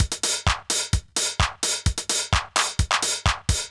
CLF Beat - Mix 4.wav